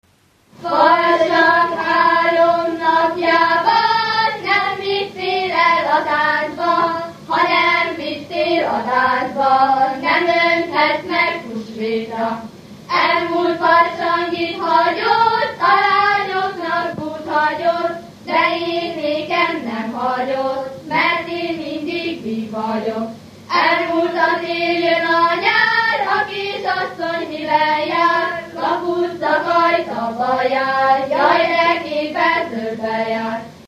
Alföld - Pest-Pilis-Solt-Kiskun vm. - Galgamácsa
Műfaj: Karikázó
Stílus: 1.1. Ereszkedő kvintváltó pentaton dallamok
Szótagszám: 7.7.7.7
Kadencia: 8 (5) 5 1